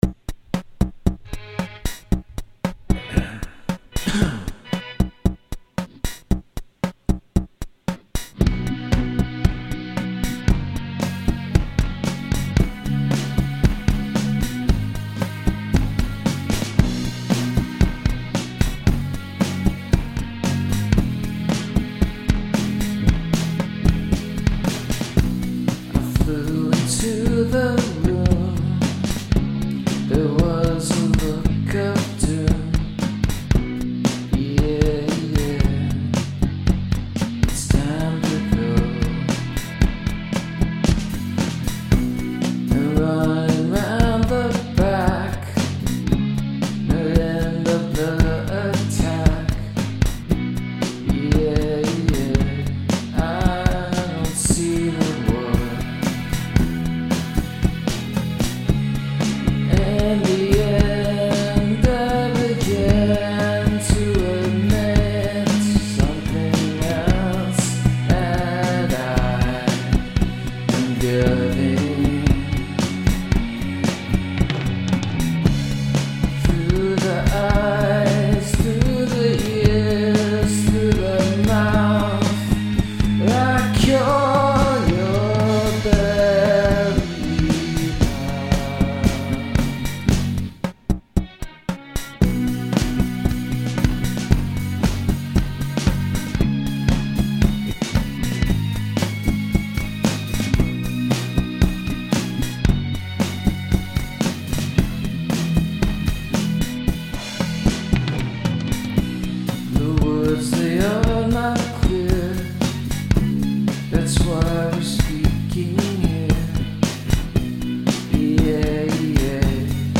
So forgive my total disregard for the rules of proper mixing.
It's Japanese for "No" or "No Thanks".
Most of the elements are things I'm familiar with, particularly the mellotron strings (which could probably be stripped down and sound less like noodling, which is what it is) and the Casio beat. I stretched myself a little putting on the "acoustic" drums.
I didn't quantize the drums because I wanted to retain their "feel", for better or worse.
I like the melodic cadence at 2:50.
The vocals are shakey and raw which makes it sound very sincere, I look forward to hearing some more.